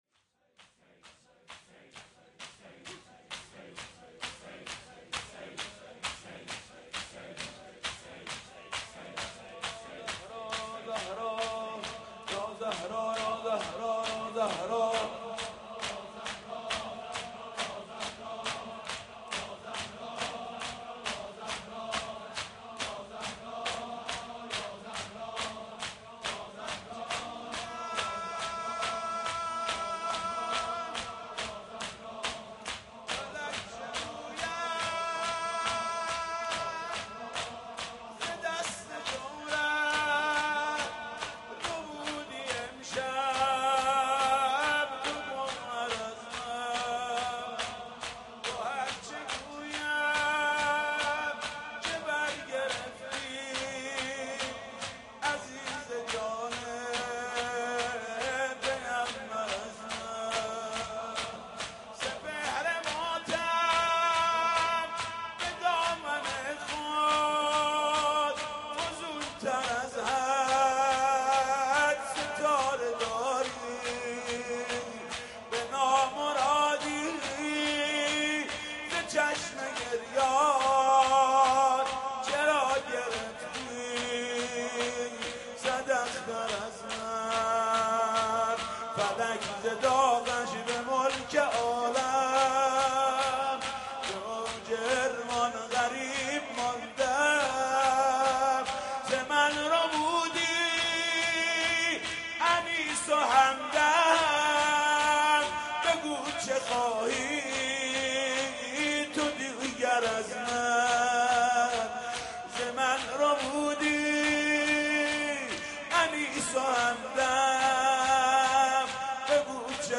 حاج محمود کریمی فاطمیه اول سال 1432 , شب دوم , شور نفس به سینه مادر, به پیش دیده حیدر, بریده بریده, مادر جون به لب رسیده, با ناله ای که علی شنیده میخونه ...